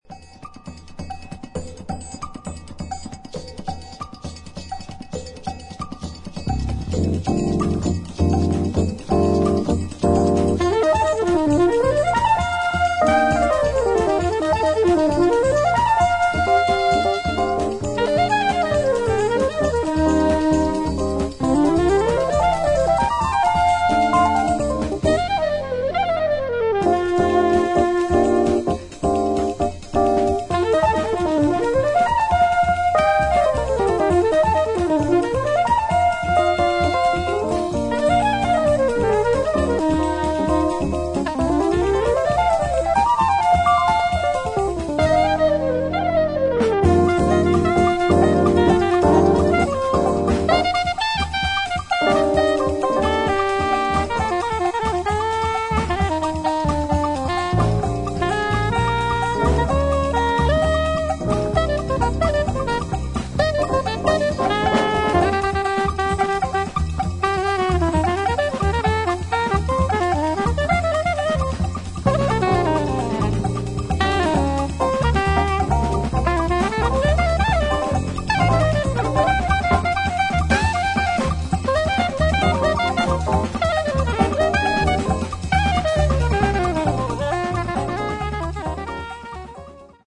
疾走感のあるブラジリアン・ジャズ